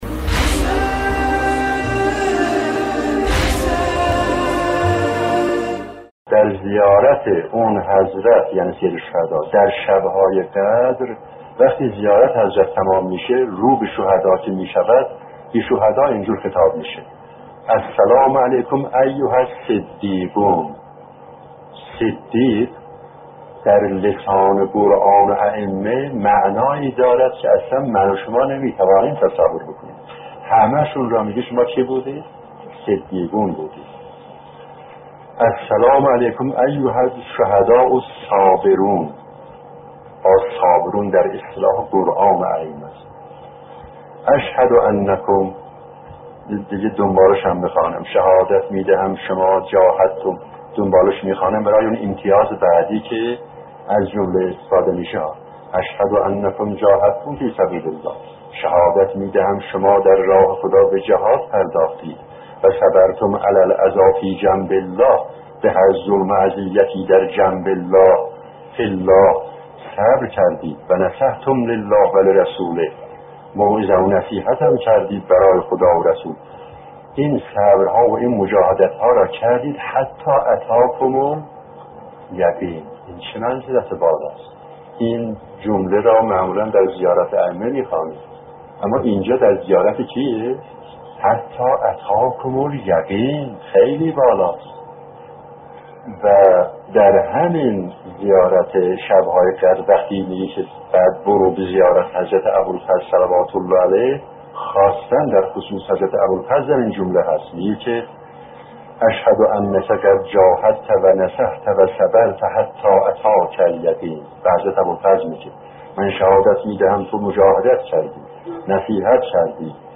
سخنرانی‌ها